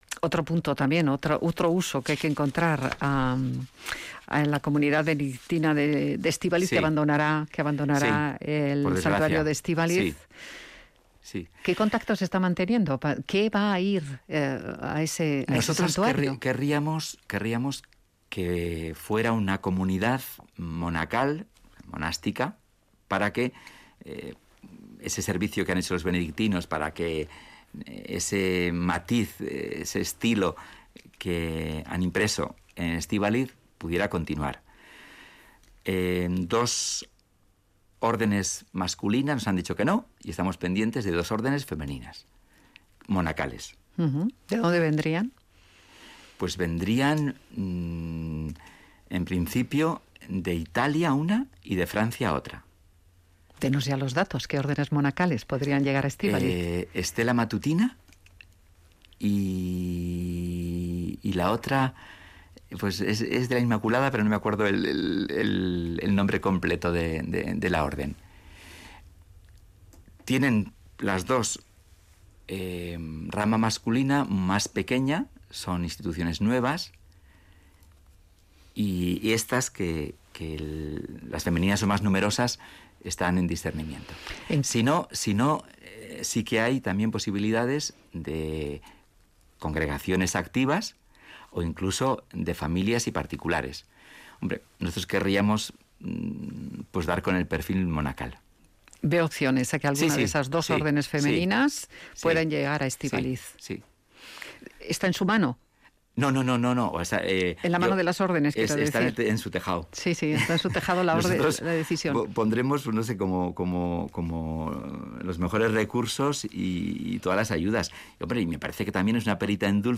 El obispo de Vitoria, Juan Carlos Elizalde, ha confirmado en Radio Vitoria que estas dos órdenes monacales femeninas, una de Francia y la otra de Italia, están estudiando ocupar Estíbaliz